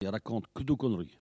Mots Clé parole, oralité
Catégorie Locution